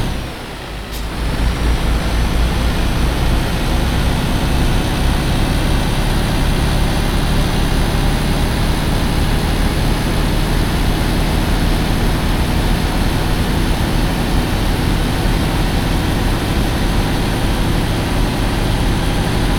Index of /server/sound/vehicles/lwcars/truck_2014actros
fourth_cruise.wav